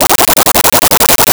Arcade Movement 08.wav